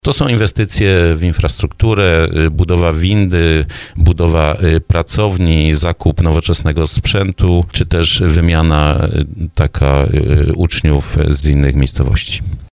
mówi starosta dąbrowski Lesław Wieczorek.